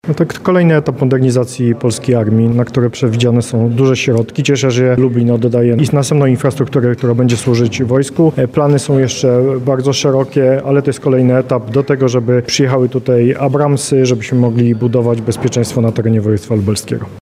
19 Lubelska Brygada Zmechanizowana powiększa swoją bazę lokalową. Dziś (27.11) odbyło się uroczyste przekazanie kolejnych budynków pod potrzeby wojska.